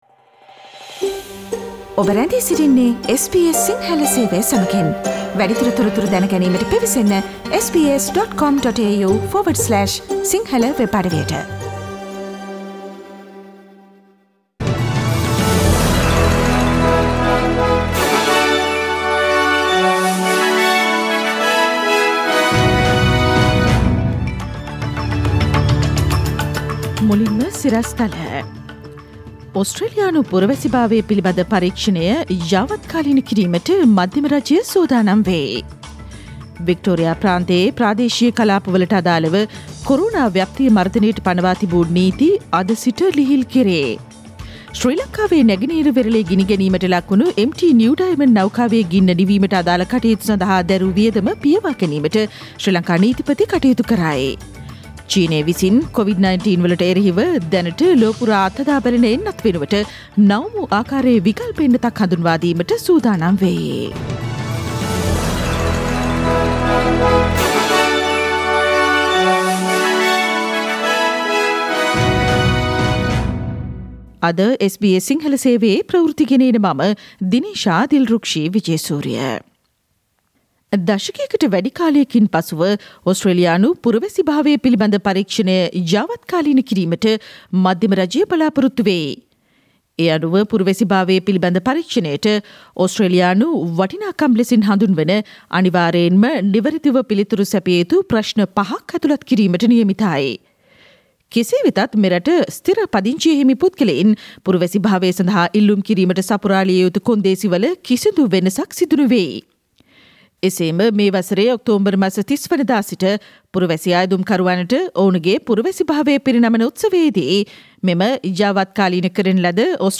Daily News bulletin of SBS Sinhala Service: Thursday 17 September 2020
Today’s news bulletin of SBS Sinhala radio – Thursday 17 September 2020.